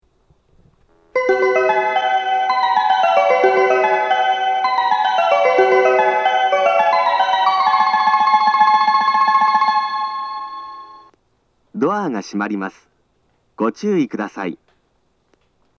発車メロディー
余韻切りです。